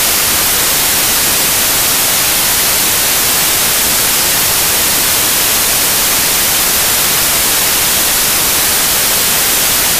Rumore acustico
In un sistema analogico audio il rumore termico di manifesta come
fruscio.
rumore.mp3